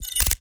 GUNMech_Scope Deactivate_11.wav